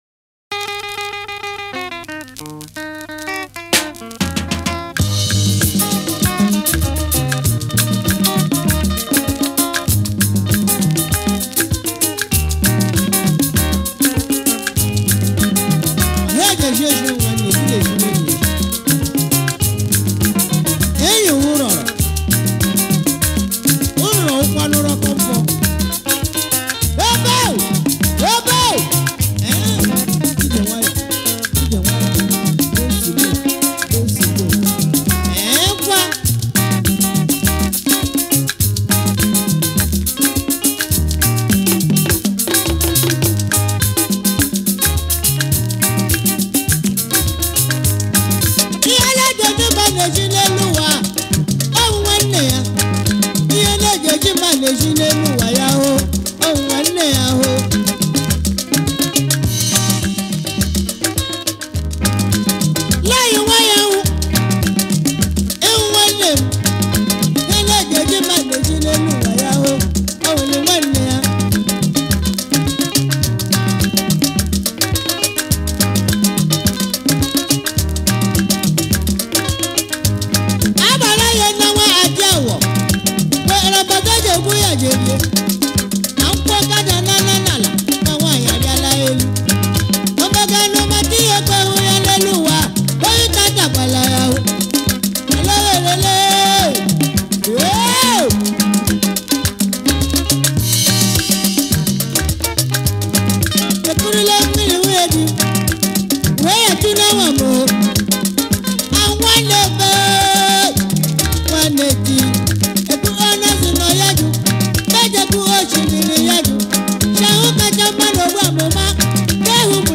Nigerian orchestra high life band